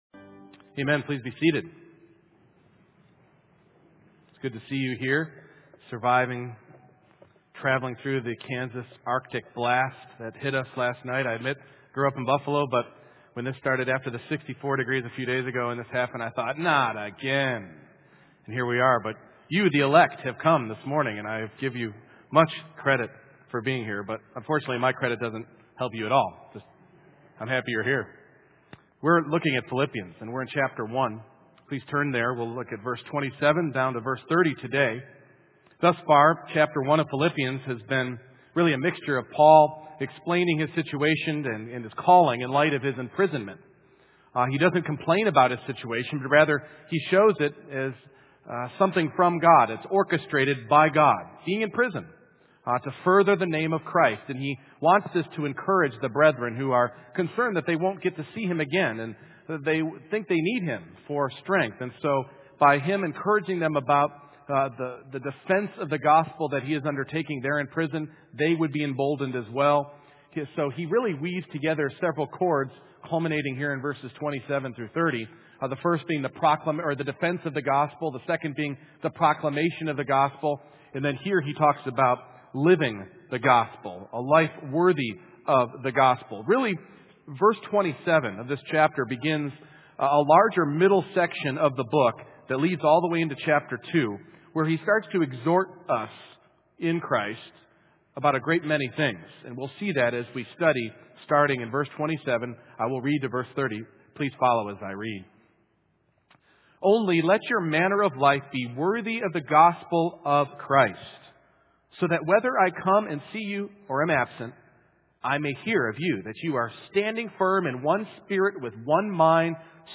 Passage: Philippians 1:27-30 Service Type: Morning Worship